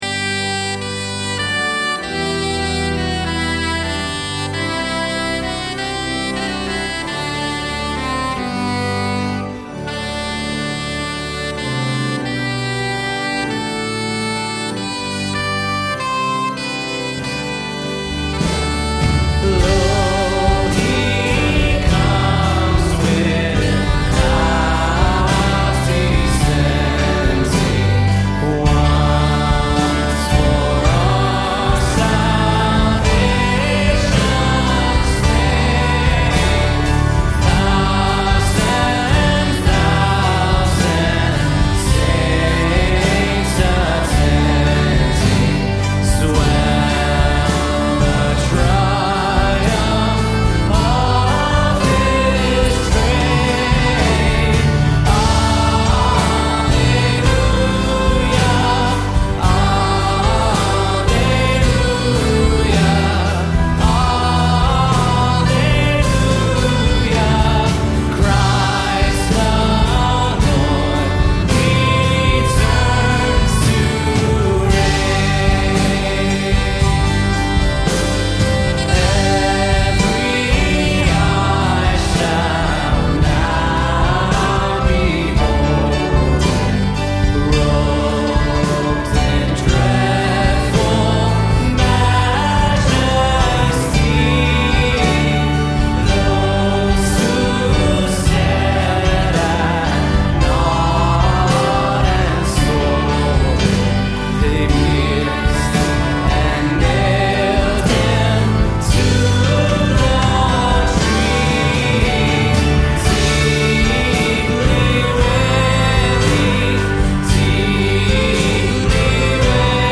Song (2010): Lo He Comes with Clouds Descending
You can read the text I used for this hymn by clicking here, and you can listen to a recording of how we played it below.
lo-he-comes-with-clouds-live.mp3